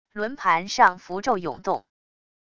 轮盘上符咒涌动wav音频